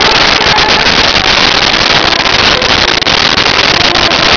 Sfx Pod Ani Buzzy B Loop
sfx_pod_ani_buzzy_b_loop.wav